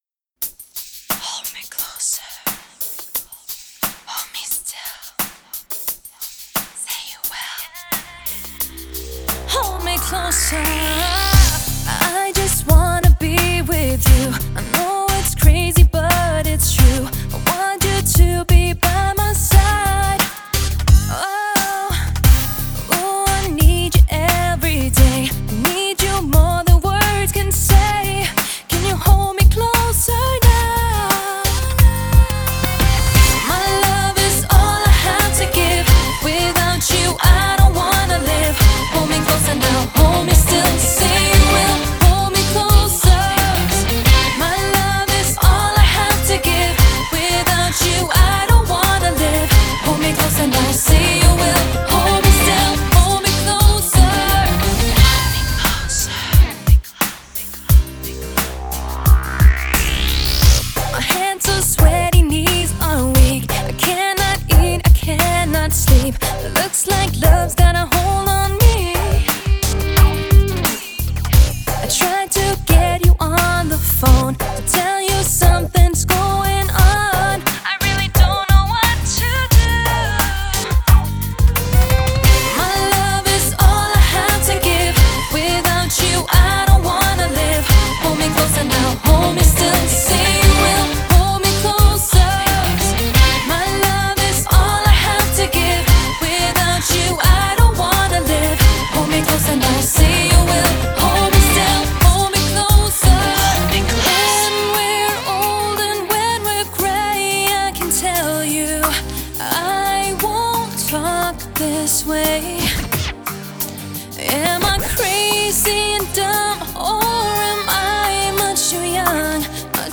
音质：112Kbps 44khz  立体声